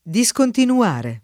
discontinuare
discontinuare [ di S kontinu- # re ]